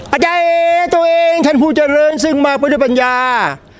หลายคนใช้เอฟเฟกต์เสียงนี้เป็นเสียงมีมเพื่อพากย์วิดีโอตลก ๆ ของพวกเขา